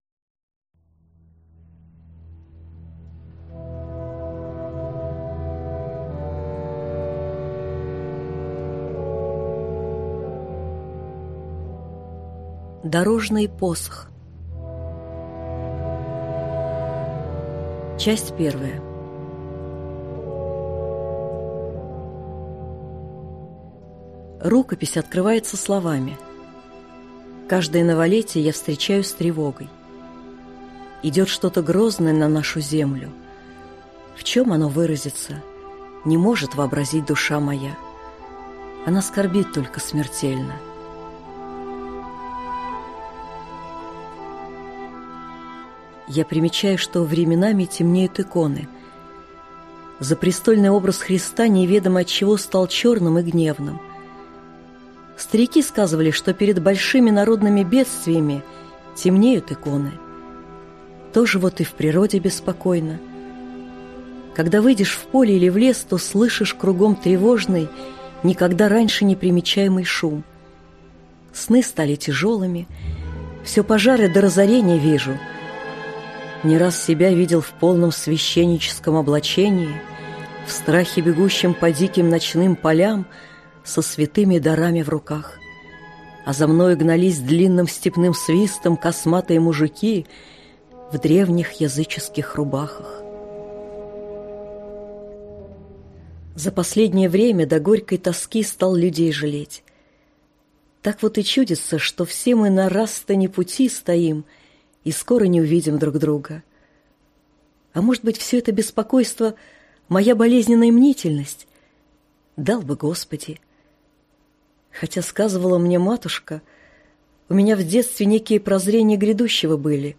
Аудиокнига Дорожный посох | Библиотека аудиокниг